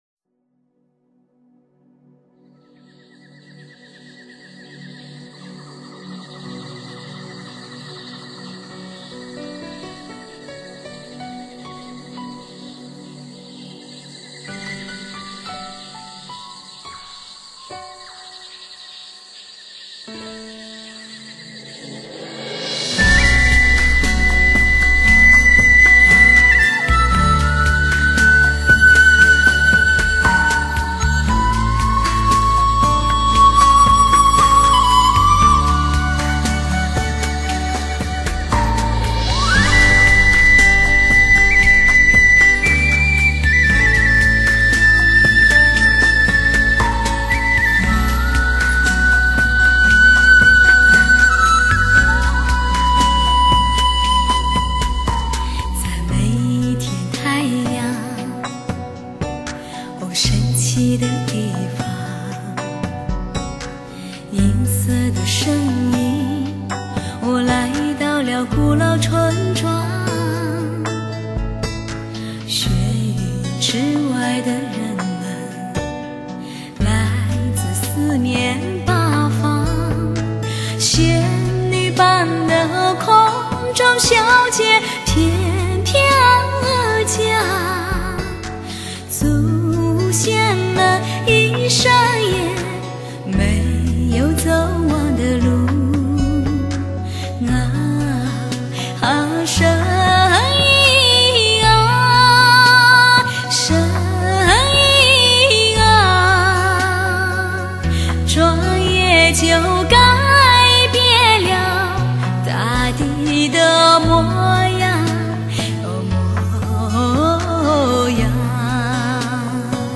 3D音效HI-FINEW AGE车载
真正实现车内空间6.1Simulation 360度环绕HI-FI音效！
漂逸悠长奔放的音乐
好听，空旷韵味十足的音乐前奏，谢谢楼主